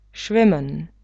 plavat (640x521)plavat schwimmen [švimn]